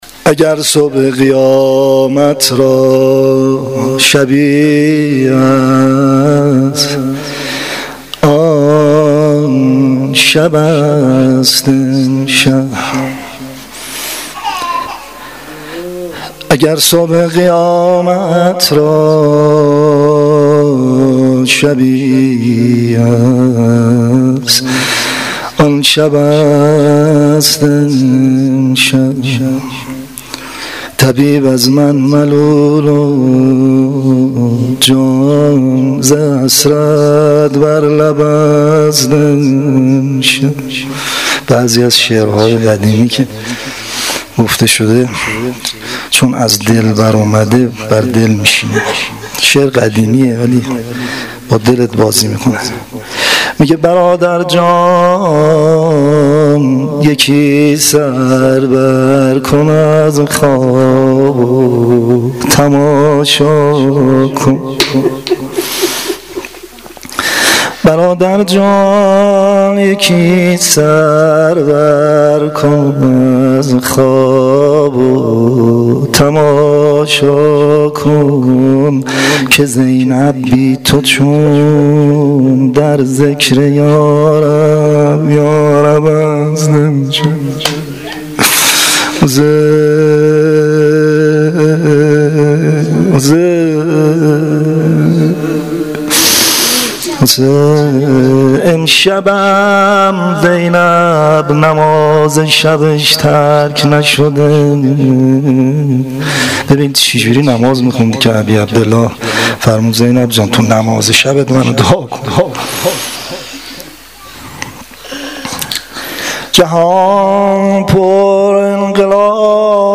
• Shabe11 Moharram1396[02]-Monajat.mp3